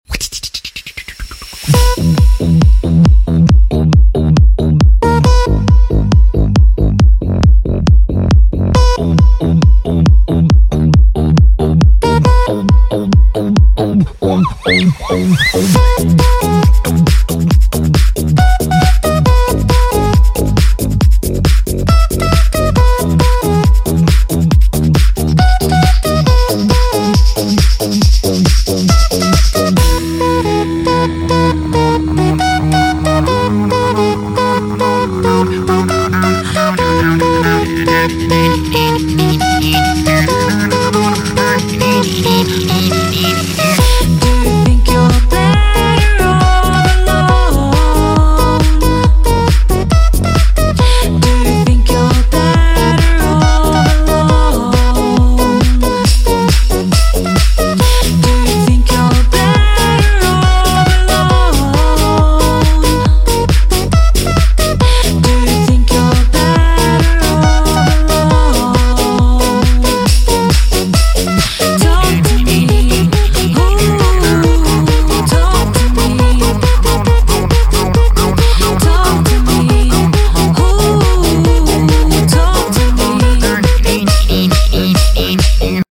beatbox cover